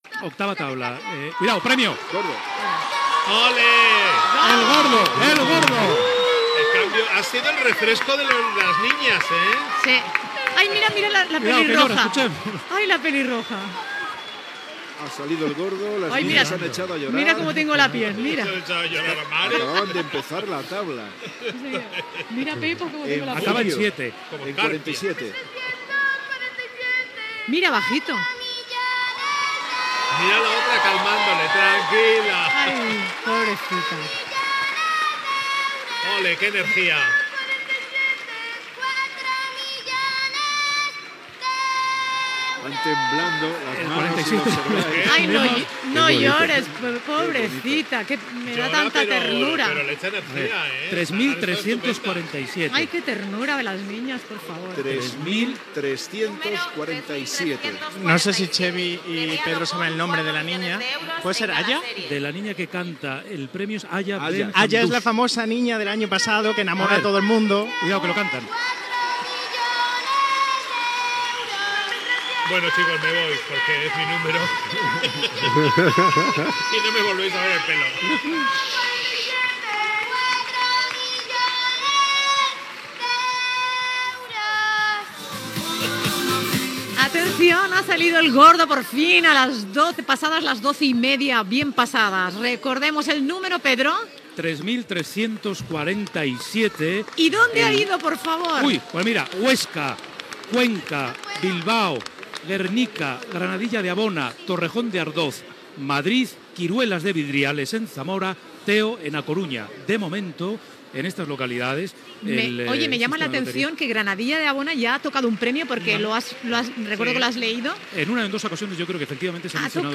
Transmissió de la rifa de Nadal espanyola en el moment que surt el primer premi. Número, reacció de les nenes que el canten i localitats agraciades
Info-entreteniment